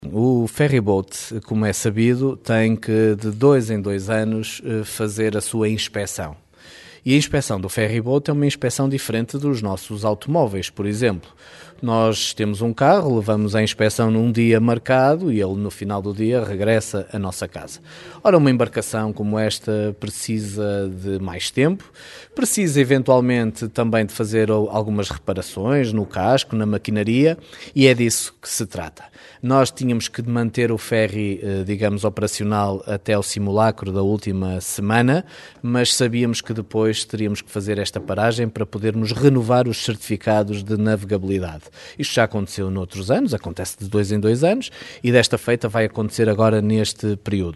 O ferryboat Santa Rita de Cássia, que faz a ligação entre caminha e La Guardia interrompeu temporariamente as travessias. A embarcação vai ser alvo de trabalhos de manutenção para a renovação do certificado de navegabilidade, como explicou à Rádio caminha Miguel Alves, presidente da autarquia caminhense.